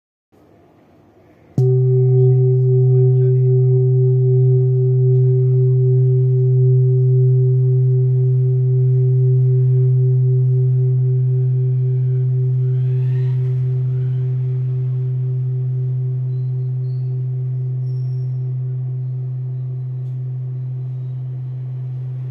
Old Hand Beaten Bronze Kopre Singing Bowl with Antique
Material Bronze
It is accessible both in high tone and low tone .